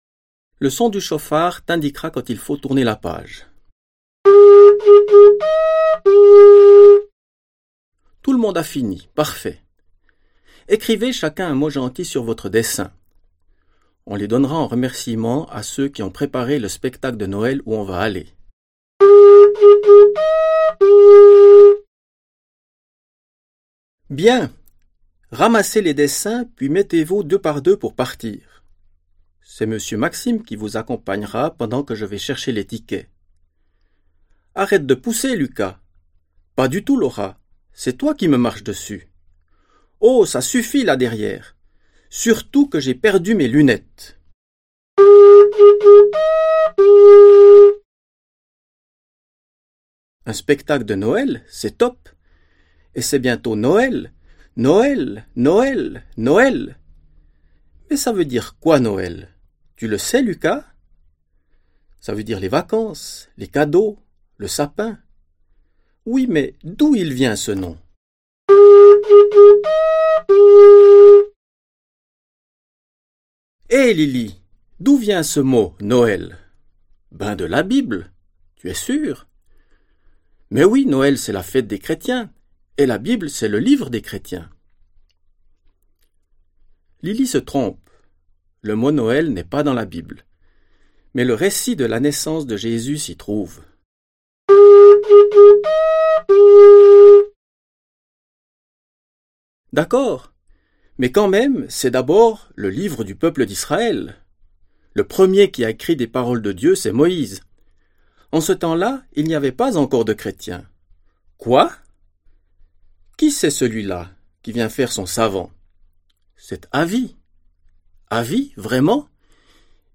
Avi 1 Livre Audio français